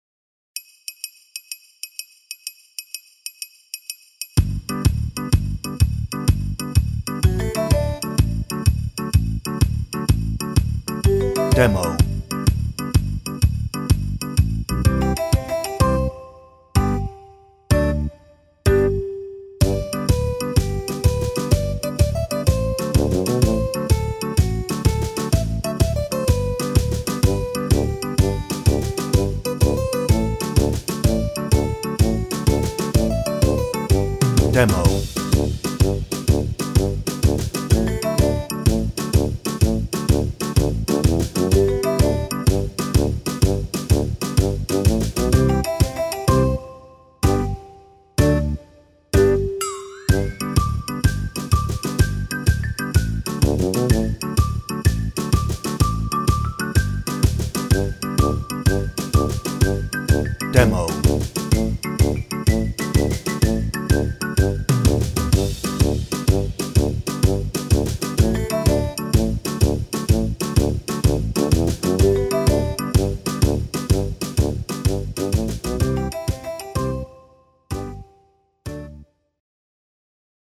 Description Reviews (0) Hoedown - No ref Vocal.
Instrumental